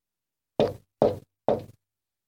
敲门声
描述：快速敲门。使用Audacity声音软件在家庭工作室录制带有Zoom h2n麦克风的麦克风。
标签： 敲门 木材 门敲
声道立体声